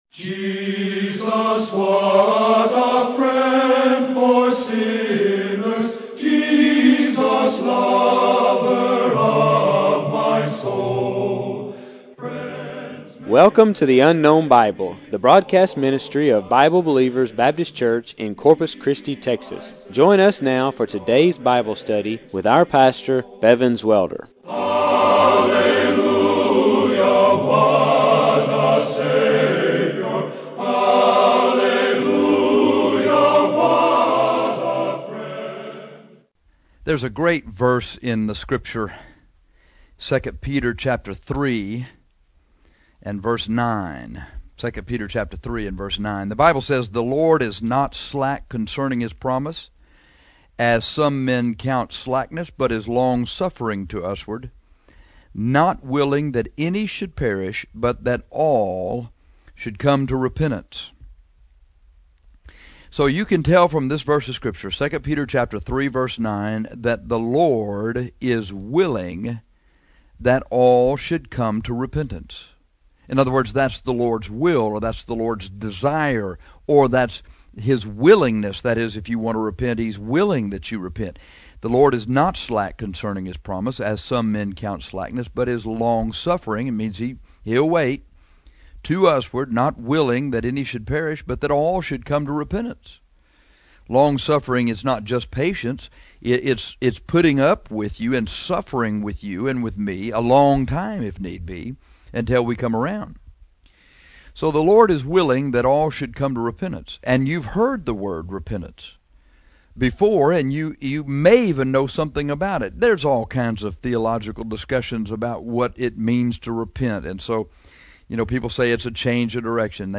How To Repent, 2 Peter 3:9 - Bible Believers Baptist Church | Corpus Christi, Texas